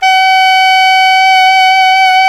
SAX ALTOMP0K.wav